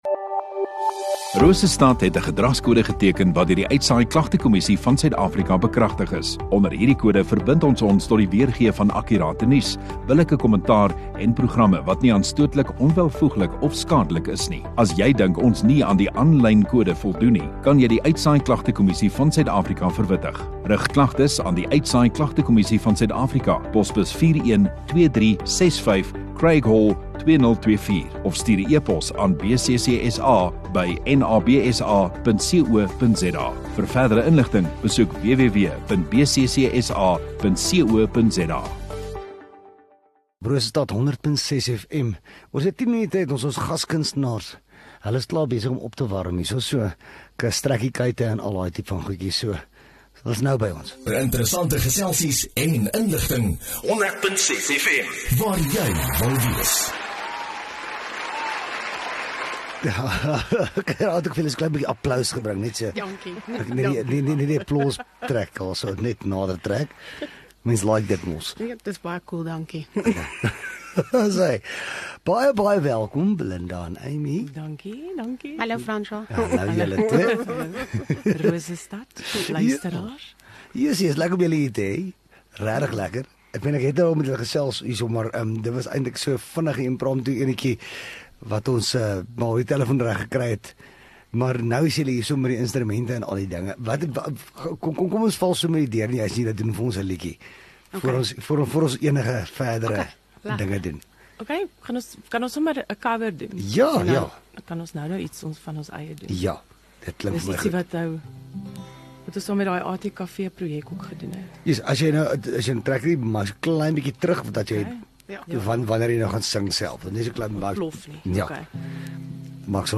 Radio Rosestad View Promo Continue Radio Rosestad Install Kunstenaar Onderhoude 7 Jul Kunstenaar